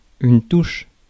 Ääntäminen
France: IPA: [tuʃ]